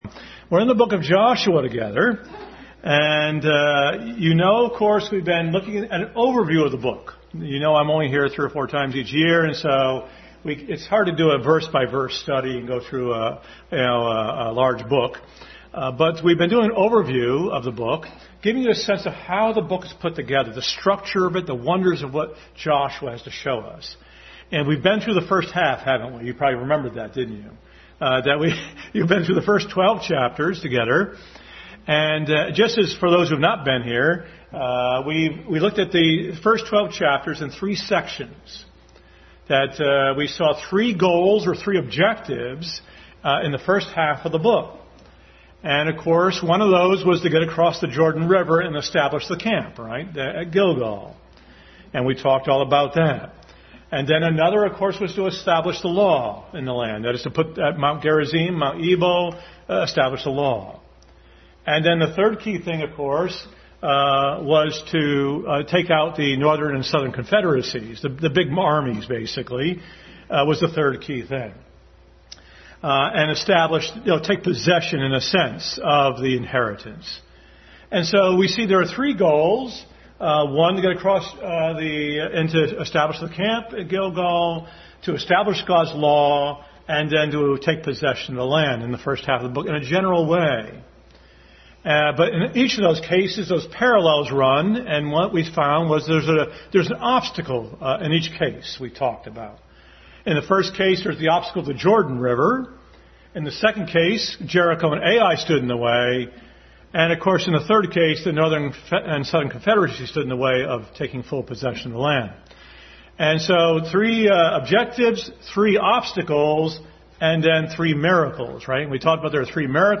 Adult Sunday School.